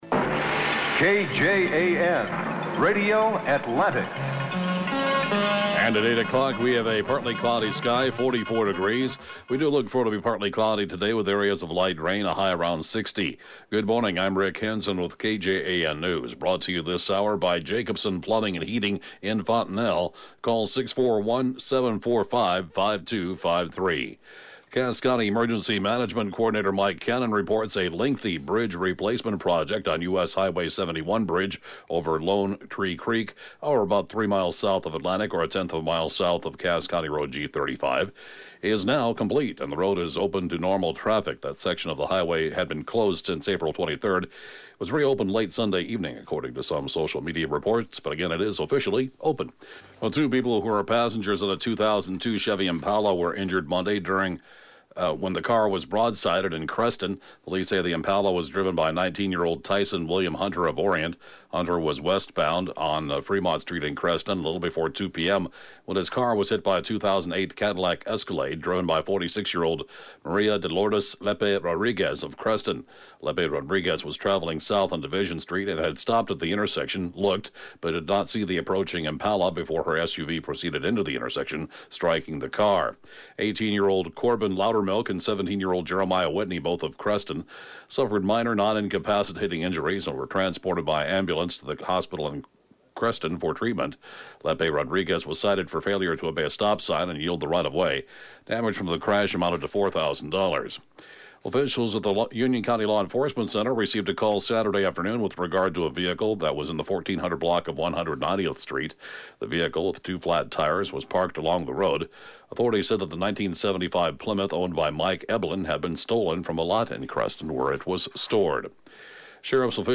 (Podcast) KJAN Morning News & funeral report, 6/15/2017
The area’s top news at 7:06-a.m.